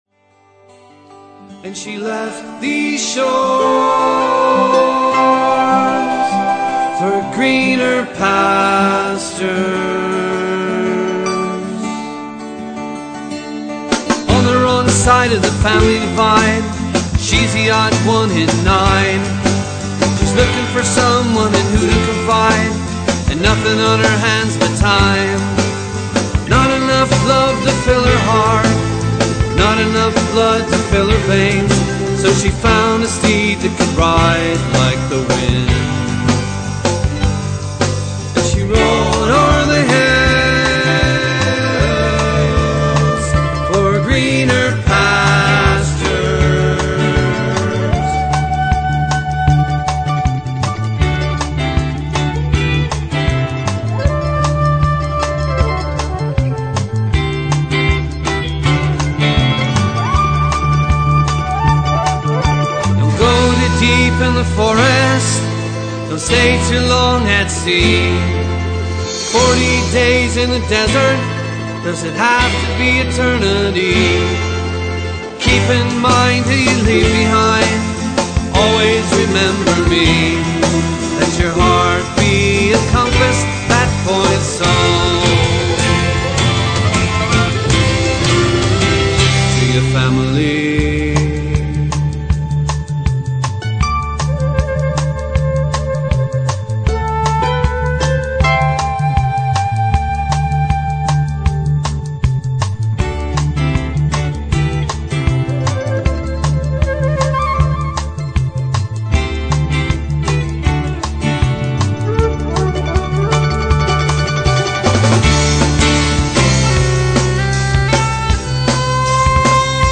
They perform Irish folk music and popular Irish tunes.